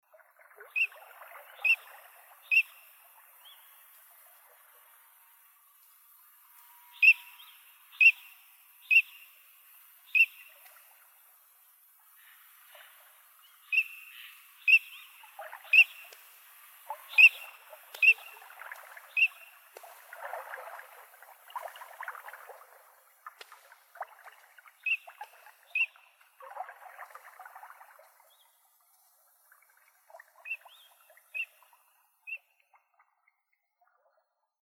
Yleisimpien ”riistasorsien” ääninäytteitä